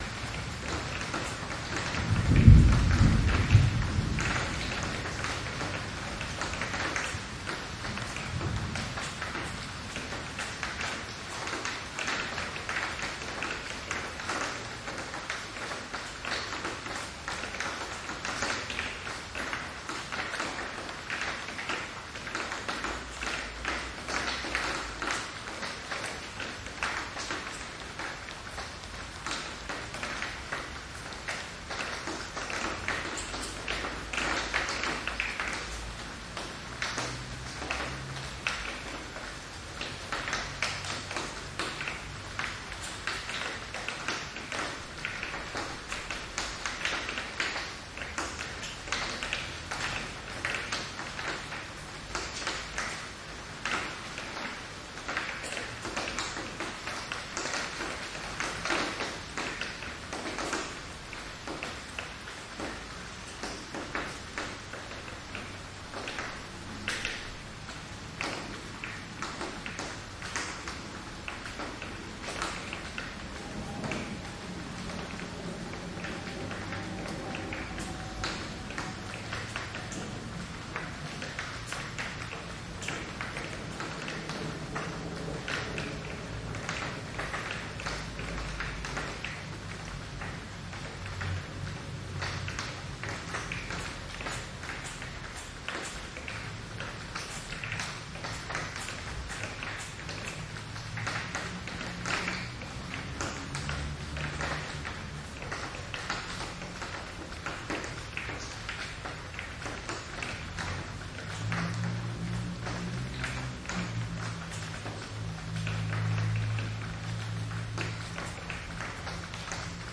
无人机 " 城市氛围单声道
描述：在一个非常寒冷的夜晚（17℃），用我的新舒尔SM58通过四分之一打开的窗户录制了一个该死的大型（70MB）录音。9分钟的莫斯科贫民窟叨叨声，偶尔有狗的声音，有邪恶的东西朝这边走来，等等。附近有一条高速公路，它是这里的主要噪音来源，但每一个噪音都从多面墙上反弹过来，提供了一些漂亮的半自然混响。嘶嘶声水平已经被清除了一些，当然也付出了一些代价，但对我来说损失似乎是可以容忍的。
标签： 环境 城市 无人驾驶飞机 字段 记录 舒尔 SM58 城市